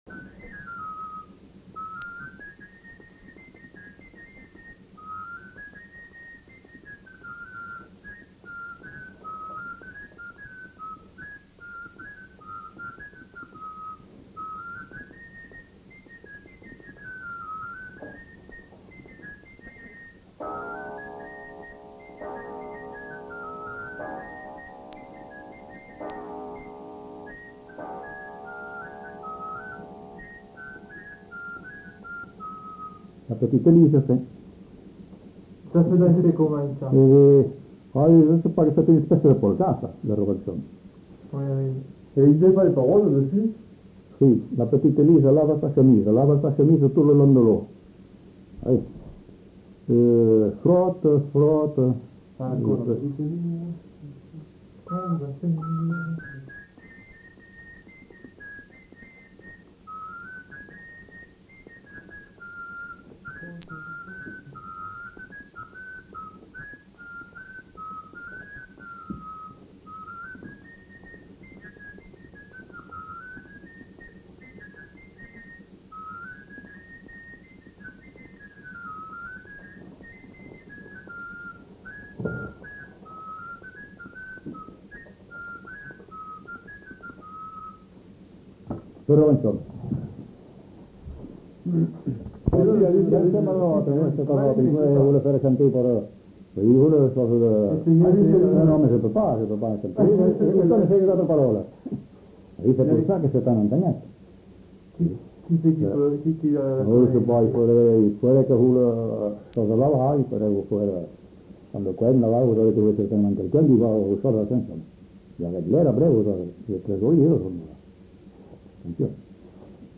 Aire culturelle : Gabardan
Lieu : Estigarde
Genre : morceau instrumental
Instrument de musique : flûte de Pan
Danse : scottish